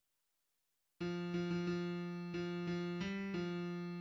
{ \clef bass \tempo 4=90 \key des \major \time 2/4 \set Score.currentBarNumber = #1 \bar "" r4 r8 f8 f16 f16 f4 f8 f g f4} \addlyrics {\set fontSize = #-2 doggy doogy } \midi{}